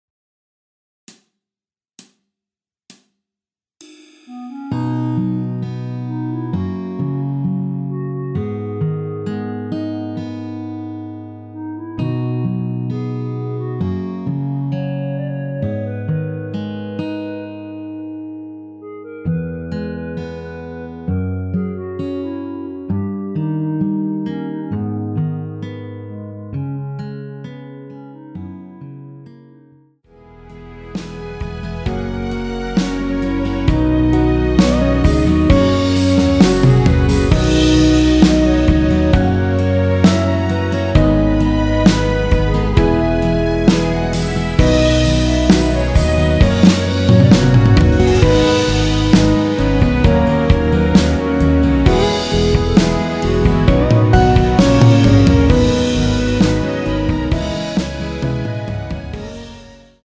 원키에서(-1)내린 멜로디 포함된 MR입니다.(미리듣기 확인)
멜로디 MR이라고 합니다.
앞부분30초, 뒷부분30초씩 편집해서 올려 드리고 있습니다.
중간에 음이 끈어지고 다시 나오는 이유는